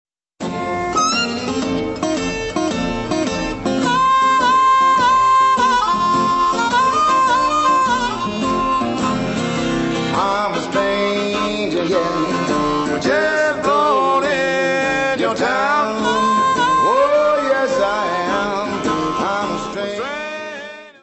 Área:  Jazz / Blues